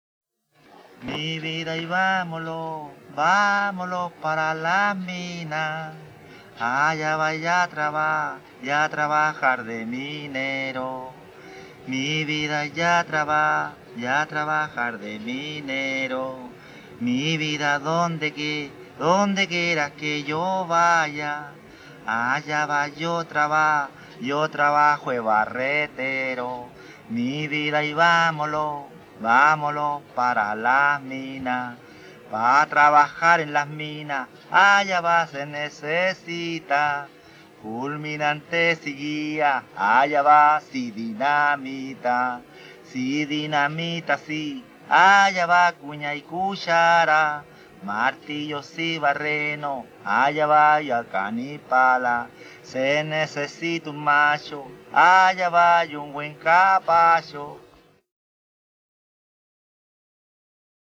a capela
Música tradicional
Cueca
Música folclórica